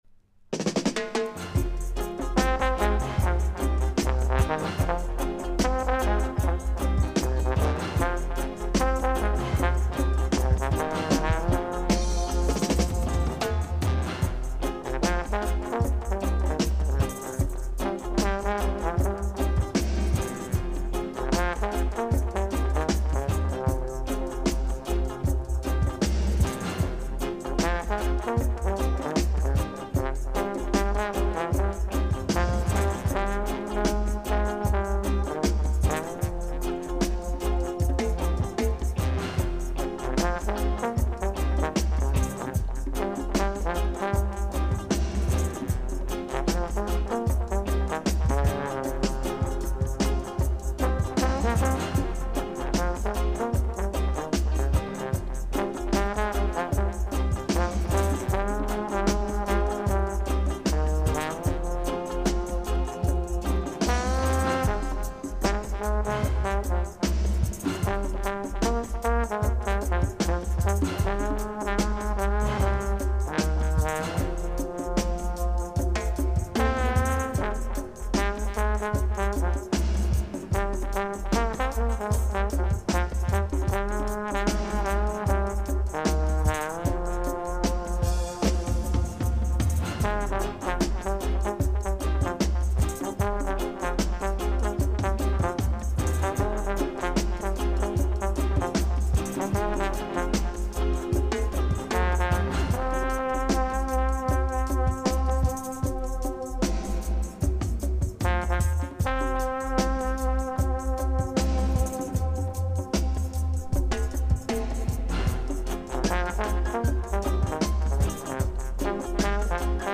The first Rootikal Expression Session landed in the heart of Brixton